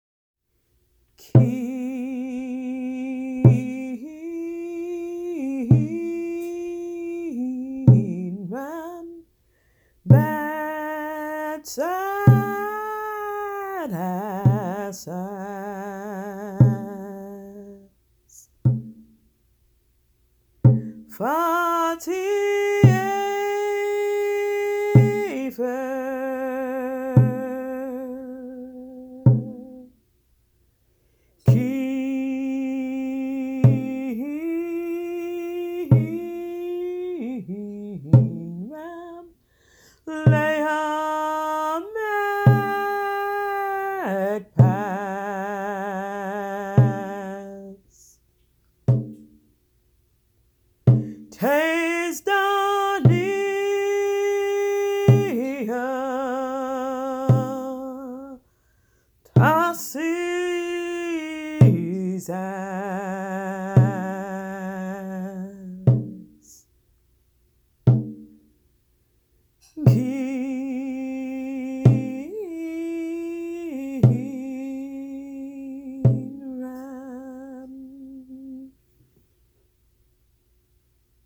Light Language peace chant
Peace-chant.mp3